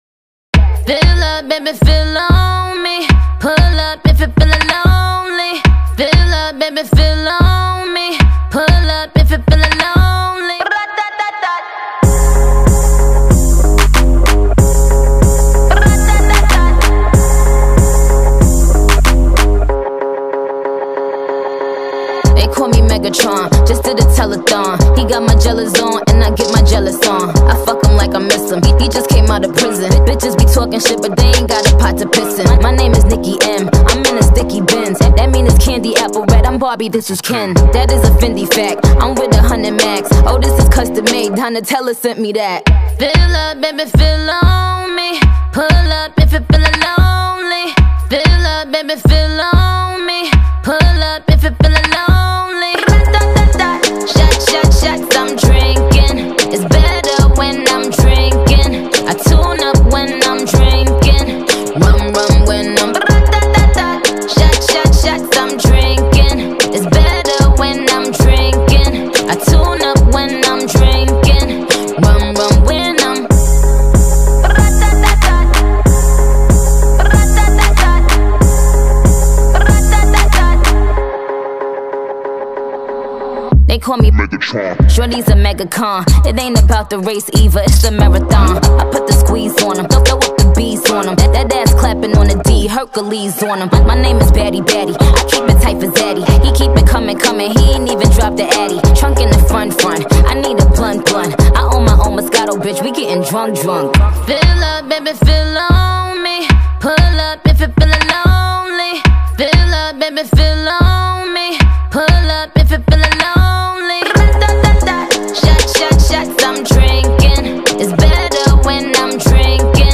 This song is a club bangger!!!